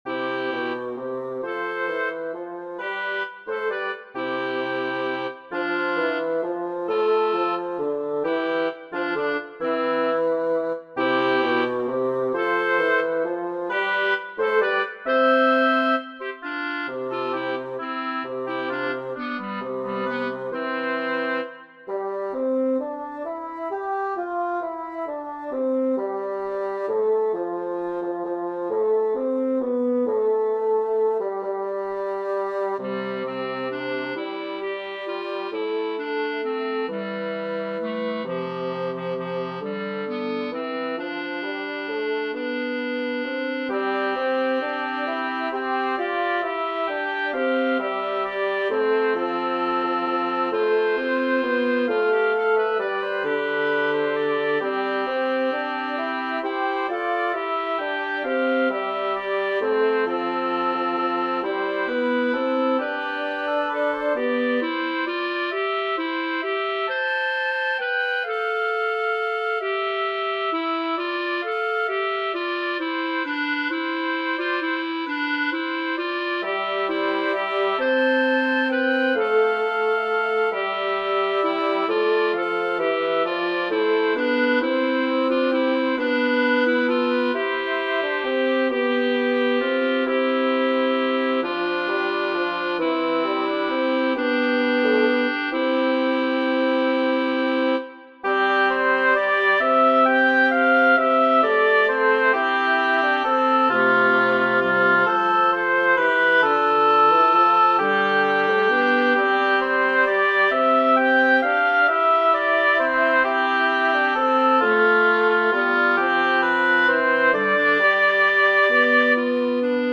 Mixed Woodwind & String Ensembles
Oboe, Clarinet, Bassoon